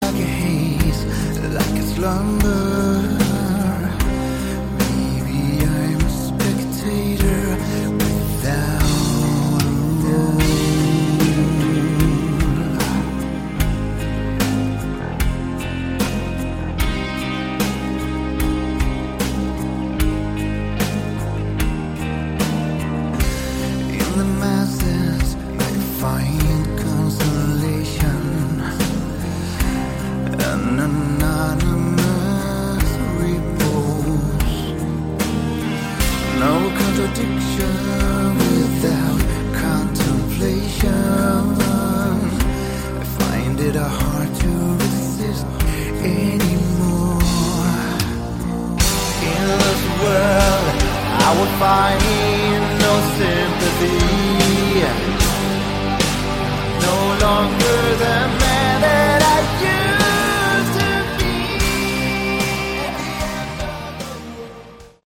Category: Hard Rock/Melodic Metal
vocals, keyboards
guitars
bass
drums